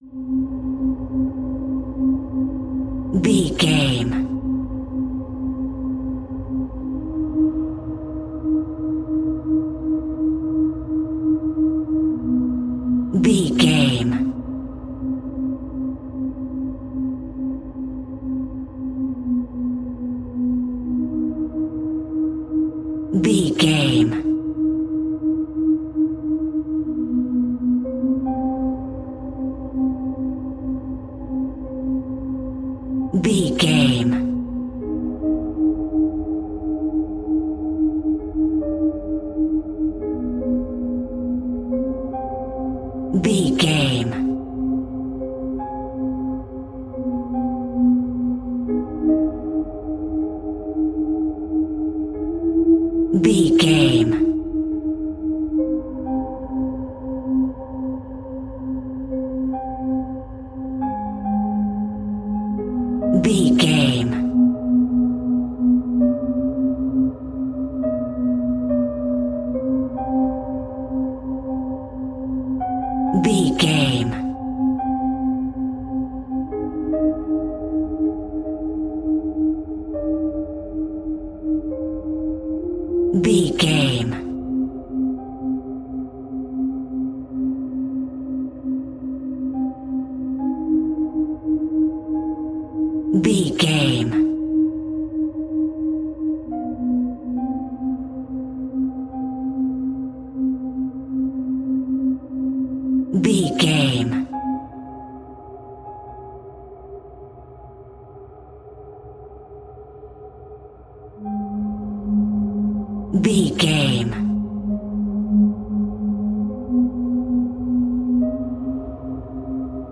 In-crescendo
Thriller
Aeolian/Minor
tension
ominous
eerie
horror music
Horror Pads
horror piano
Horror Synths